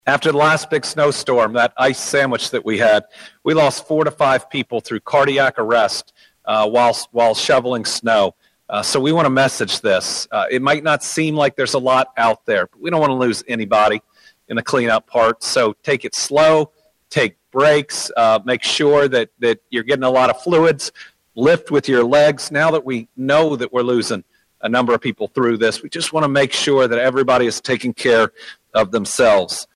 During a Wednesday morning “Team Kentucky” update from Frankfort, Governor Andy Beshear noted Kentucky State Police has received more than 12,500 calls for service in the last 24 hours.